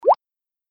Effect_Tick.ogg